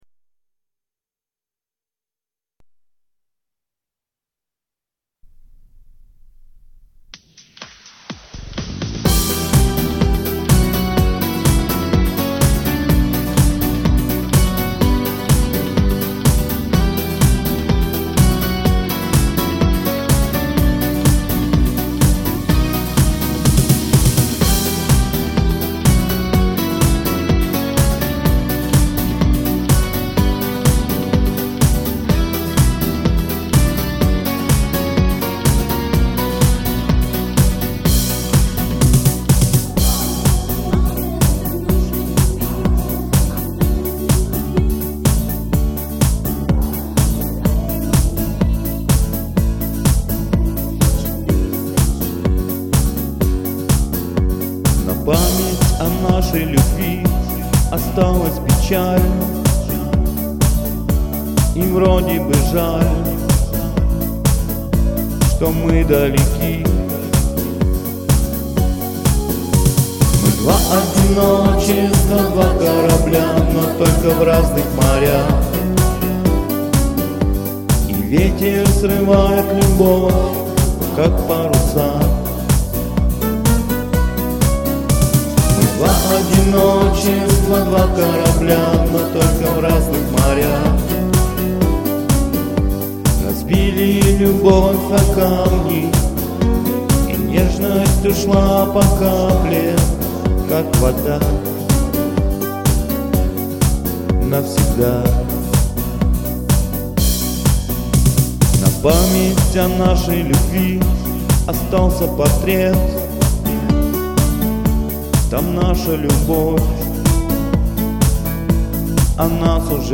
Исполнитель: